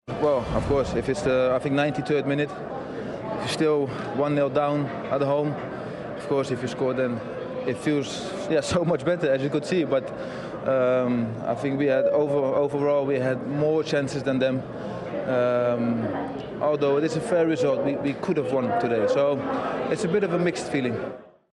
The United goalscorer gives us his reaction to Manchester United 1 Chelsea 1